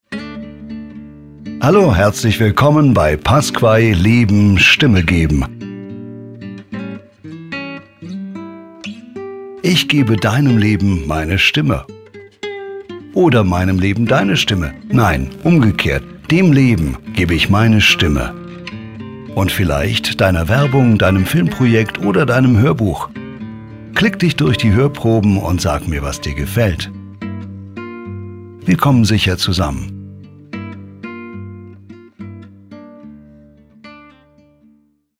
Startseiten-Ansage4.mp3